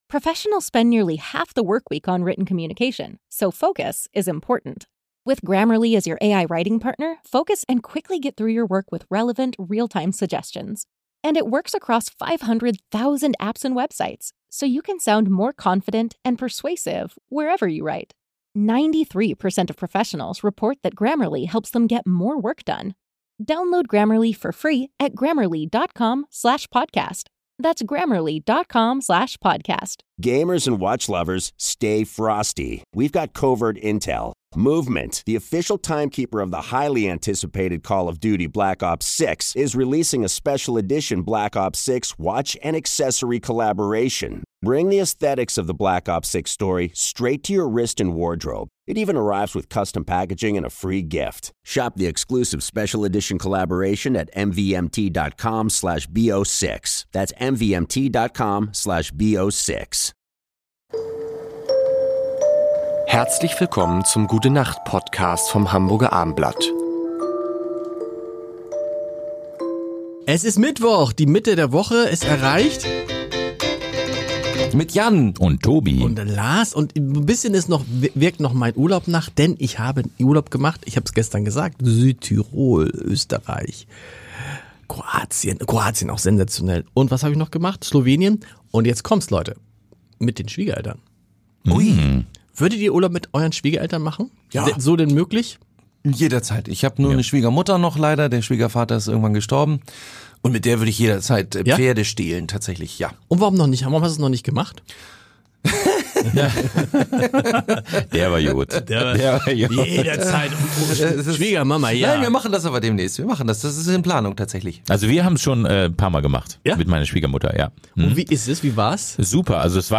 und natürlich sehr musikalische fünf Minuten. Es wird gesungen,
gelacht und philosophiert, und Stargäste sind auch dabei.